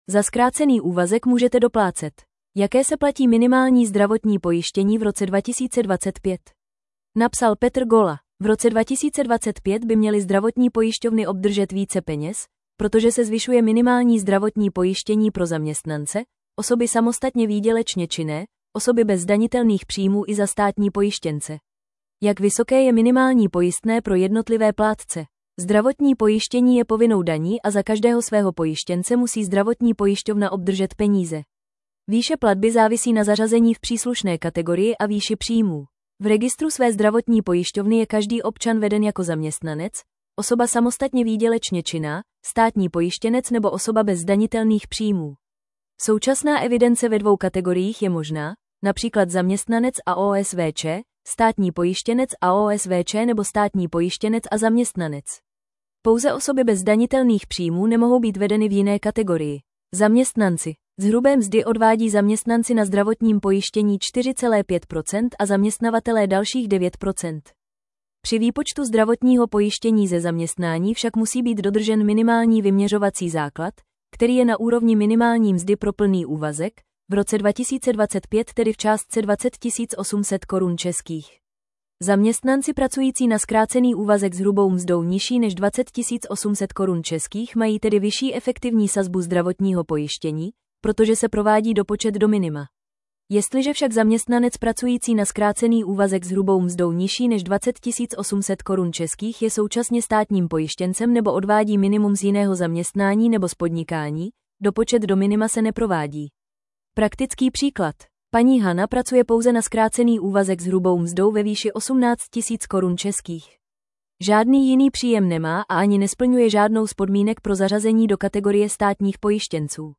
Tento článek pro vás načetl robotický hlas.